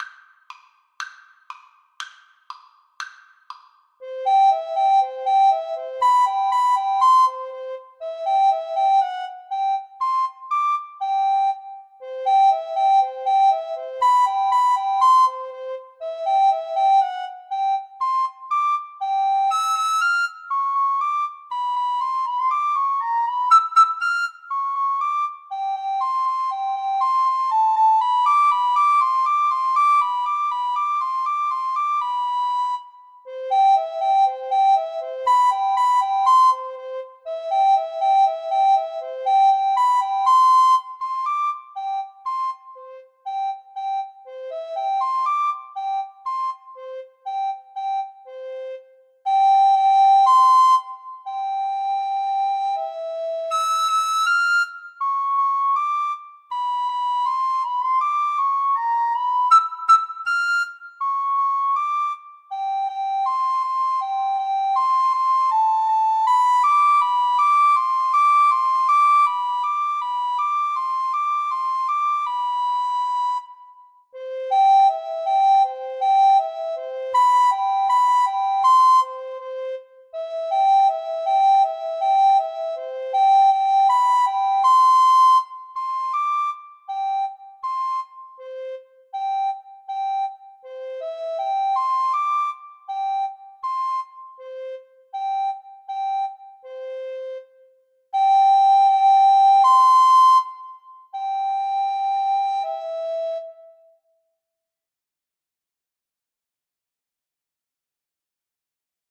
Descant Recorder 1Descant Recorder 2
No. 12. Allegro (View more music marked Allegro)
2/4 (View more 2/4 Music)
C major (Sounding Pitch) (View more C major Music for Recorder Duet )
Recorder Duet  (View more Easy Recorder Duet Music)
Classical (View more Classical Recorder Duet Music)